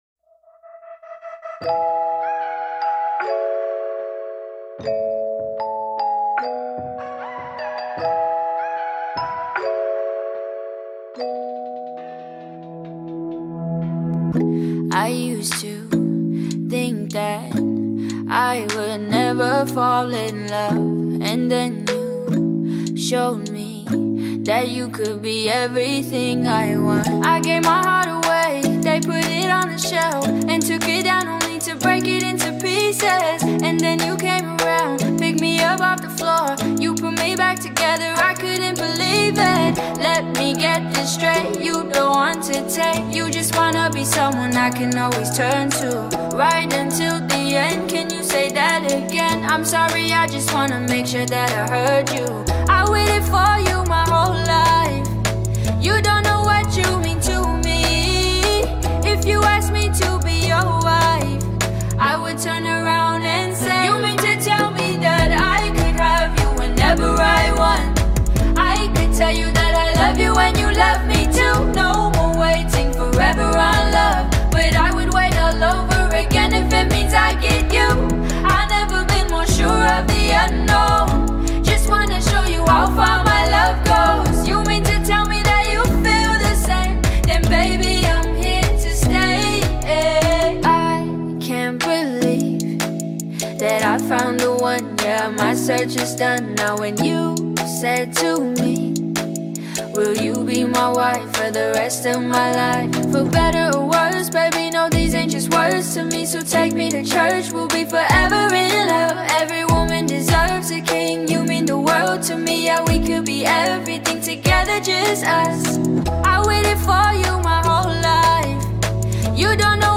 love tune
The American base R&B Singer